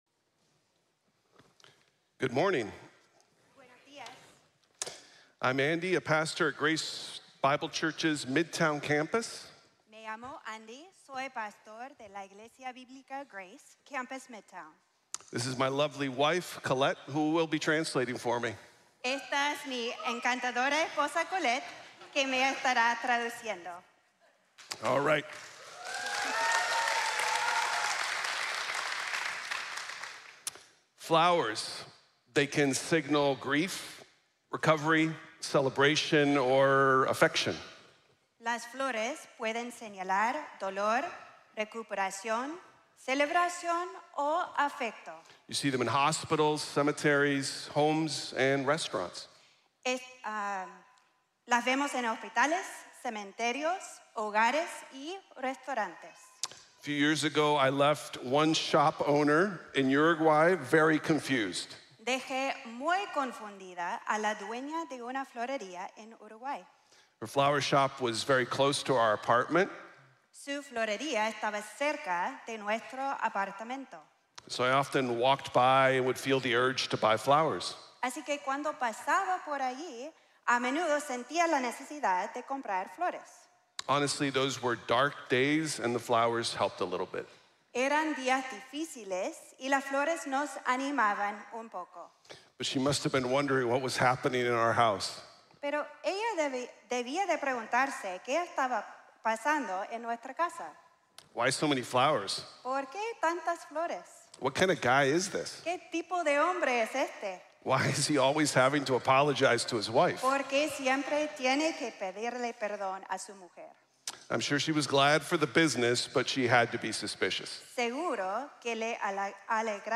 Glorious Exchange | Sermon | Grace Bible Church